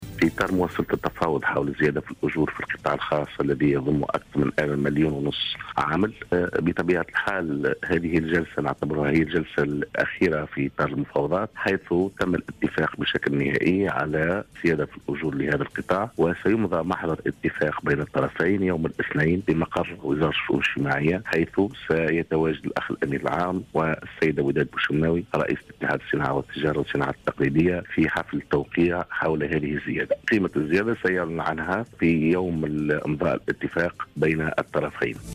أكد الأمين العام المساعد للاتحاد العام التونسي للشغل بلقاسم العياري في تصريح لجوهرة أف أم اليوم ,على إثر اجتماع وفد من الاتحاد العام التونسي للشغل ووفد عن اتحاد الصناعة والتجارة والصناعات التقليدية ووزارة الشؤون الاجتماعية ، أنه تم الاتفاق على تاريخ الـ 23 جوان 2014 للإمضاء النهائي على اتفاق الزيادة في الأجور في القطاع الخاص.